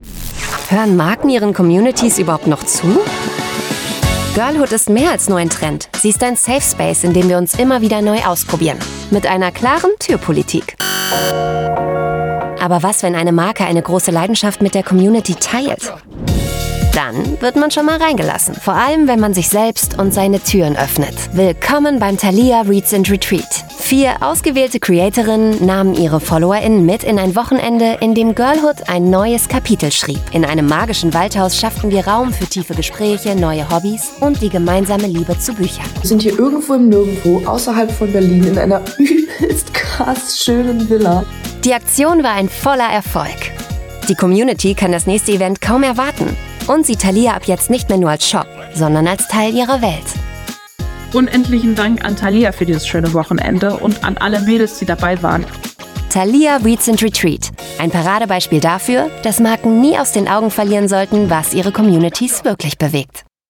Easy Jet werbung